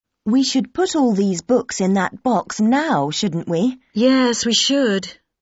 British English